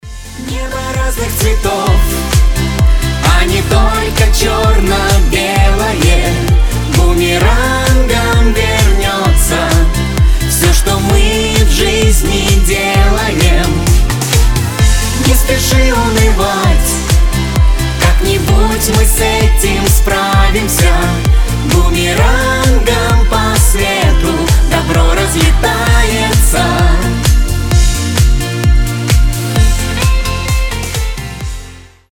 • Качество: 320, Stereo
дуэт
вдохновляющие
добрые
эстрадные